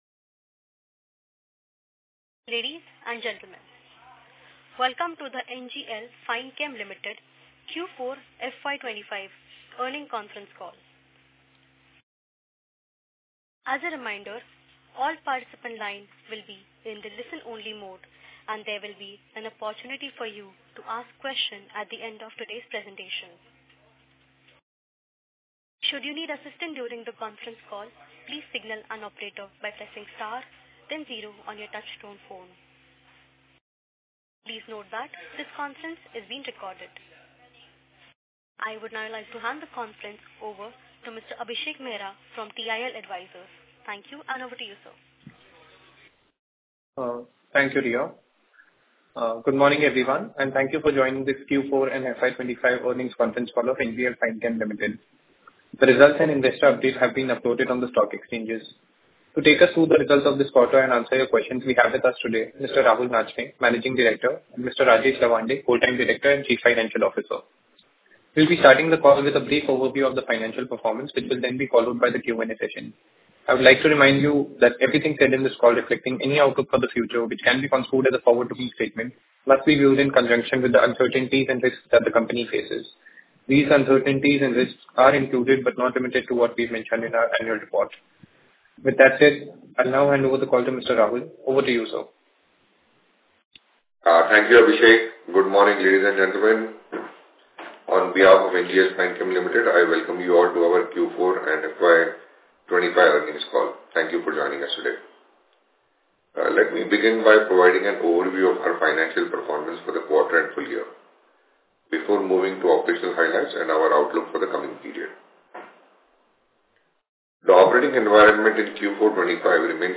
Earnings Call and Investor Meeting